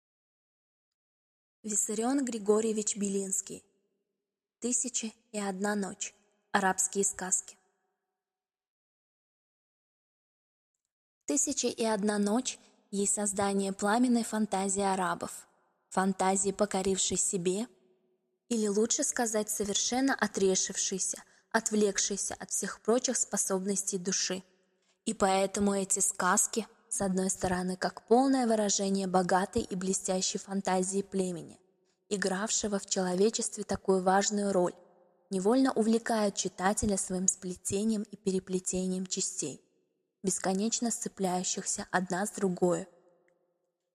Аудиокнига Тысяча и одна ночь, арабские сказки | Библиотека аудиокниг